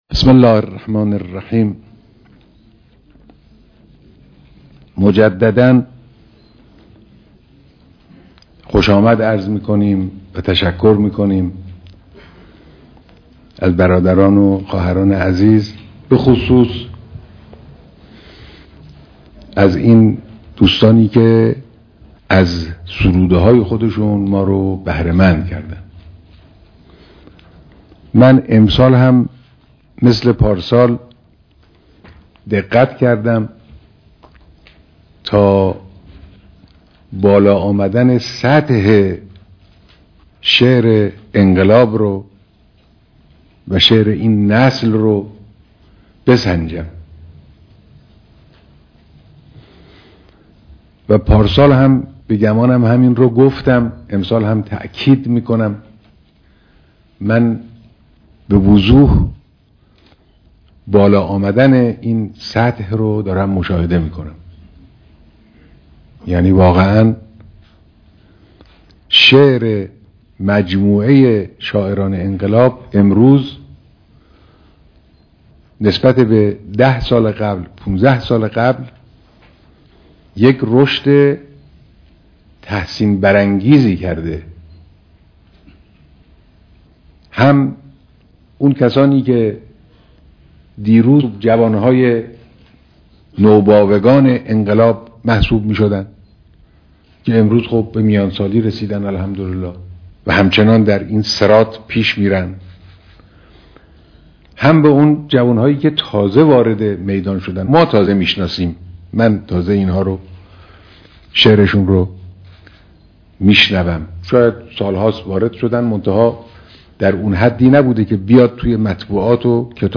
ديدار جمعی از شعرا، فرهیختگان و اهالی فرهنگ